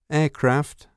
a_aircra.wav